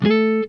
OCTAVE 3.wav